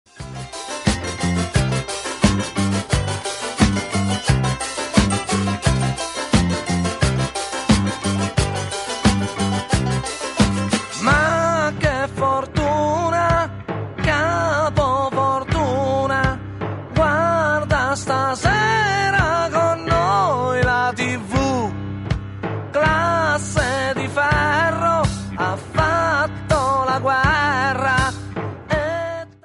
Ritmo trascinante